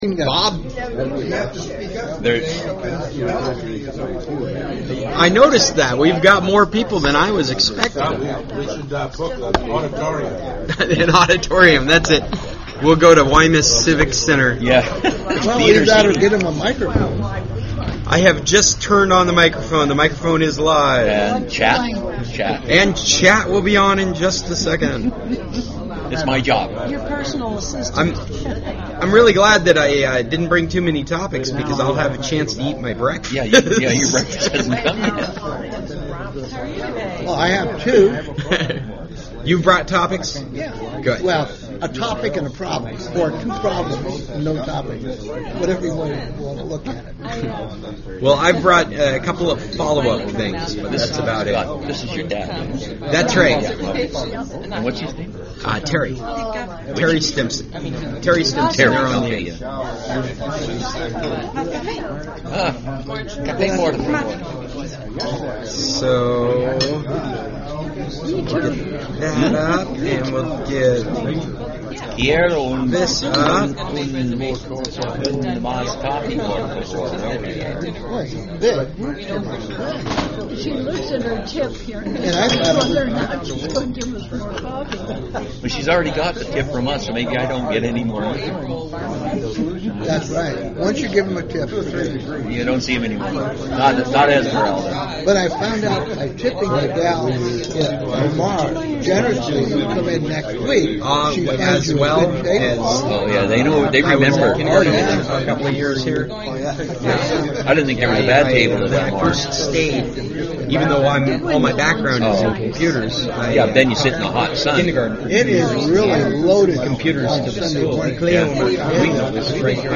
It was a huge meeting! We had our regular long table full + another three individual tables, full of people.
Well, anyway, we covered a lot (even though I was eating breakfast.)